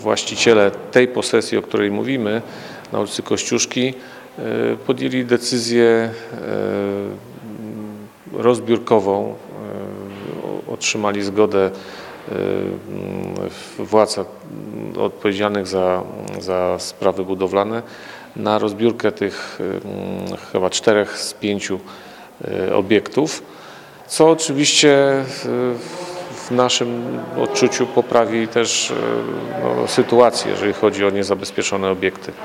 Po licznych staraniach, udało się uzyskać zgodę na rozbiórkę. Mówi Mirosław Hołubowicz, zastępca prezydenta Ełku: